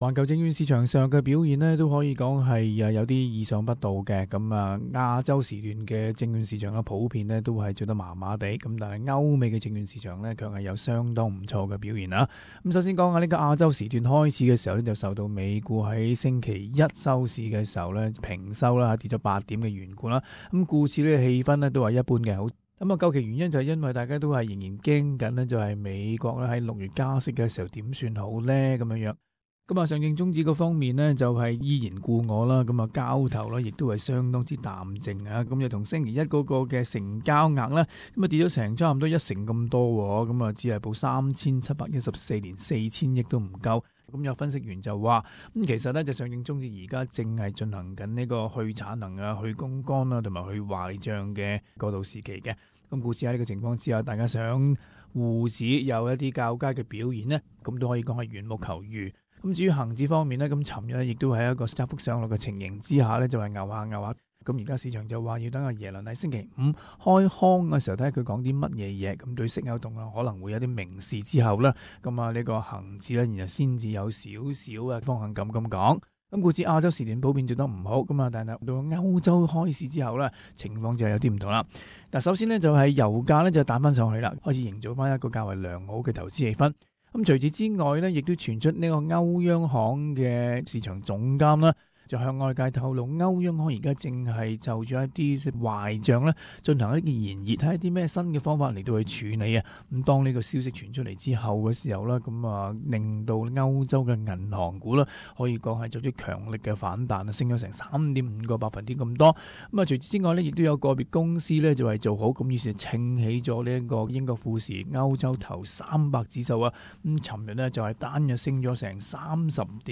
Global Finance News: Interview